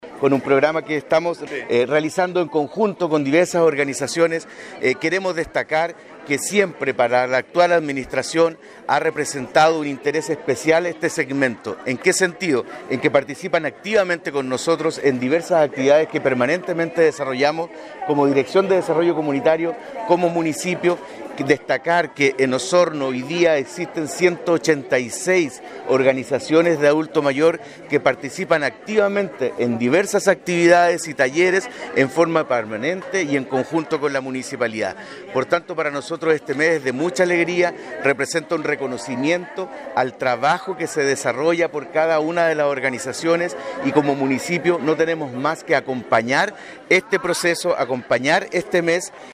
Al mediodía de ayer, en la Sala de Sesiones del Municipio, se realizó el lanzamiento oficial de las actividades que se ejecutarán durante todo octubre para celebrar el “Mes del Adulto Mayor”, en Osorno. Las actividades fueron presentadas por el alcalde (s) Claudio Villanueva, junto a concejales y concejalas, representantes de la Unión Comunal de Adultos Mayores, Fundación Caritas, la Liga Deportiva y Recreativa de Adultos Mayores  y la Coordinadora Evangélica.